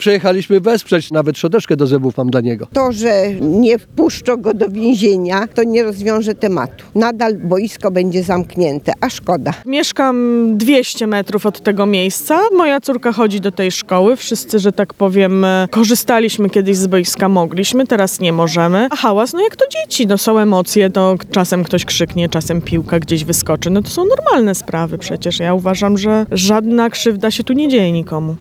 – Cieszą zapowiadane zmiany w prawie, ale grzywna nie powinna być wpłacona – mówił przed opolskim Zakładem Karnym, Paweł Maj.